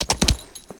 canter2.ogg